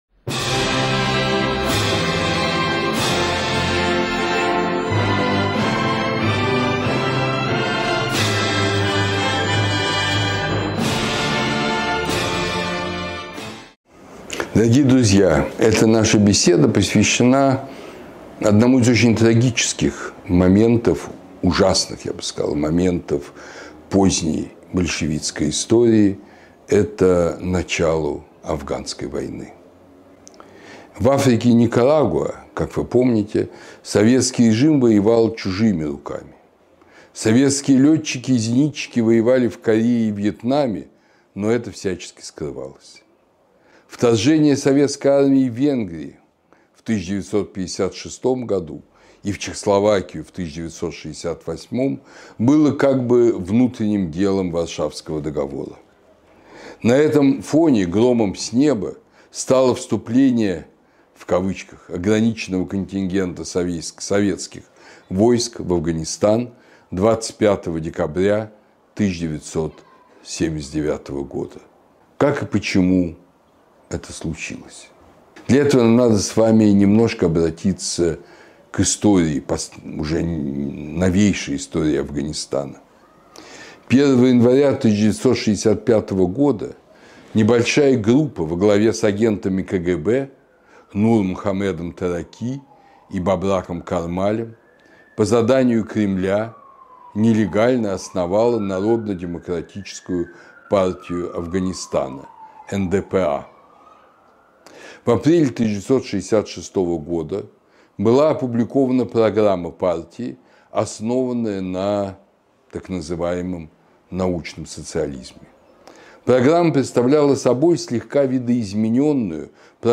Лекцию читает Андрей Зубов